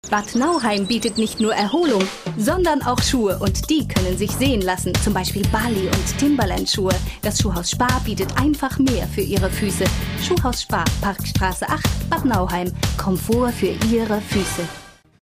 Sprecherin deutsch. Warme, vielseitige Stimme, Schwerpunkte: Lesungen, musikalische Lesungen, Rezitationen,
Sprechprobe: Sonstiges (Muttersprache):
Female voice over artist German